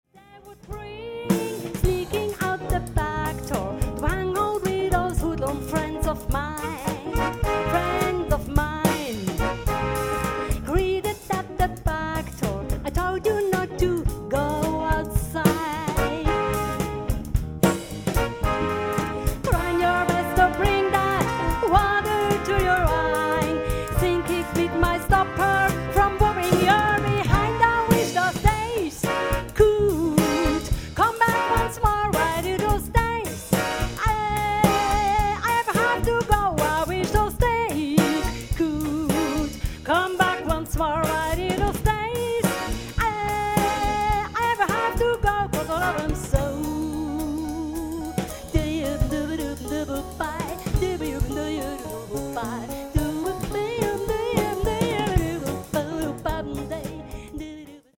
Wohlen, Sternensaal